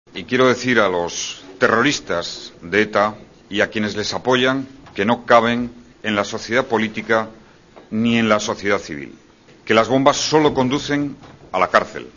Mówi premier Hiszpanii Jose Luis Zapatero